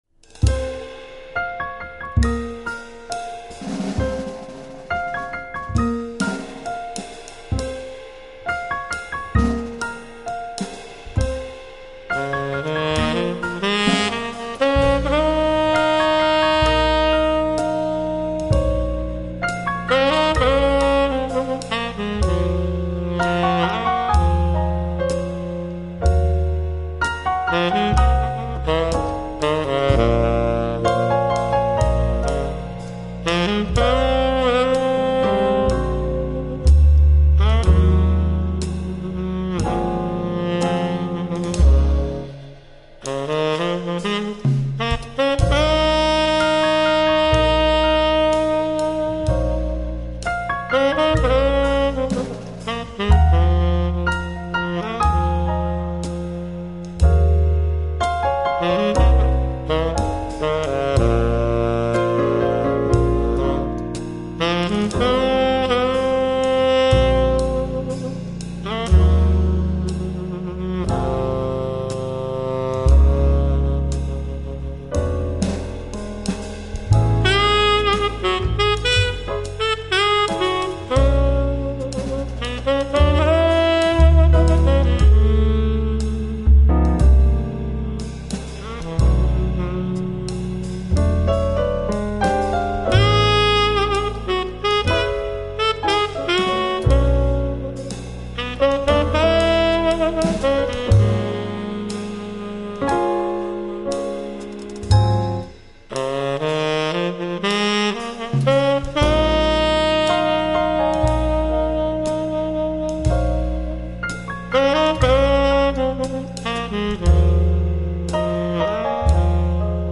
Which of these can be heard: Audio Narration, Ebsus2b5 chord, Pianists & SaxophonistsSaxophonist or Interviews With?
Pianists & SaxophonistsSaxophonist